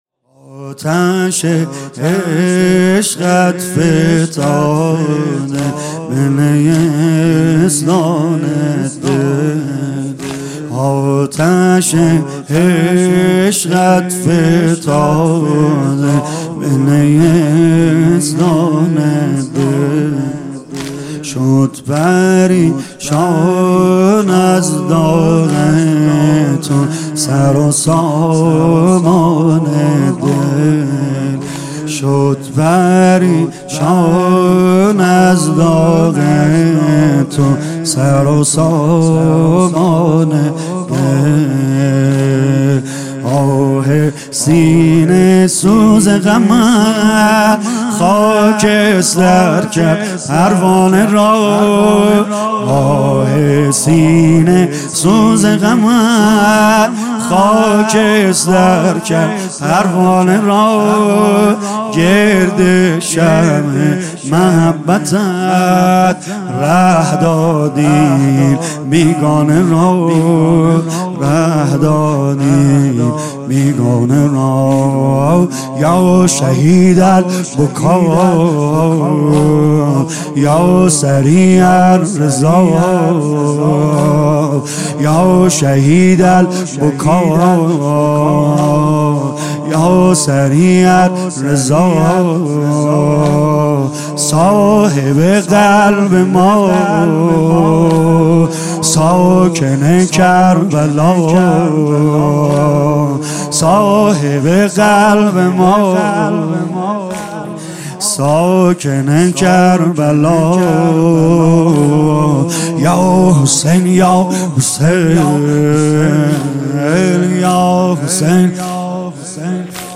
مداحی جدید سید رضا نریمانی شب اول محرم 99.05.31 هیات فداییان حسین علیه السلام اصفهان